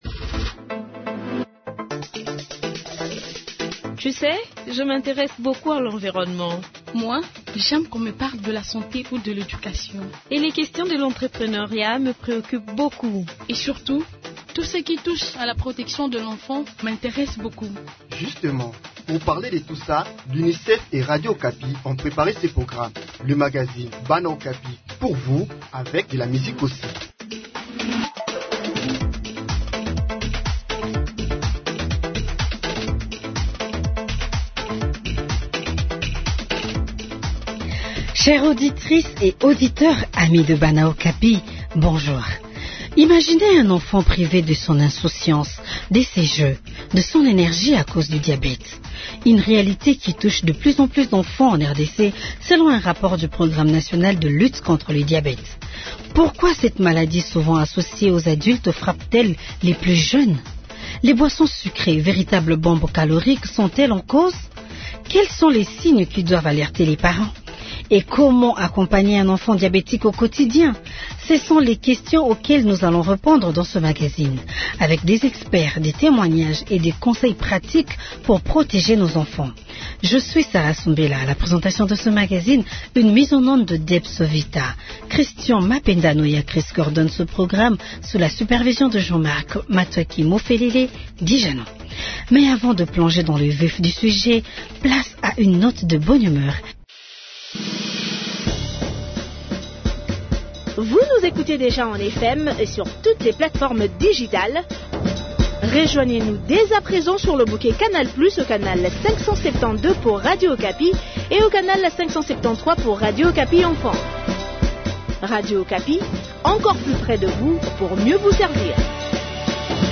Avec des experts, des témoignages, et des conseils pratiques pour protéger nos enfants.